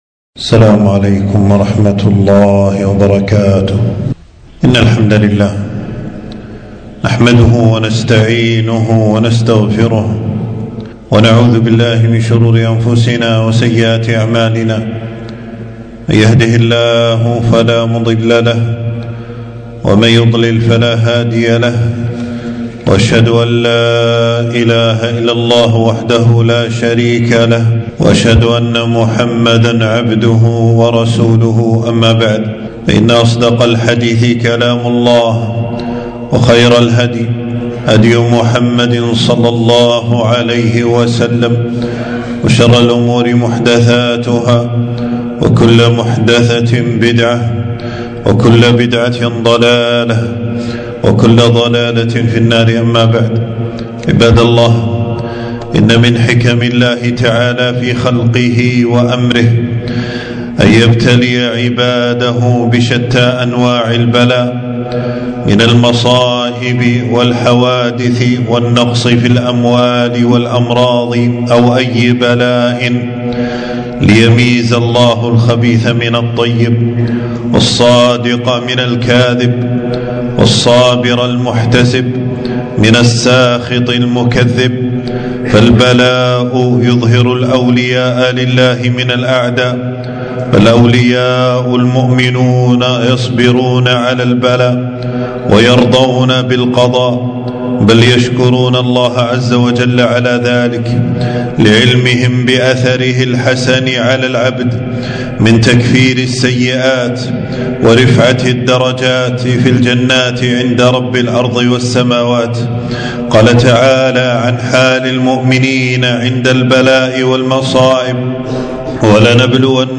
خطبة - (إنما يوفى الصابرون بغير حساب)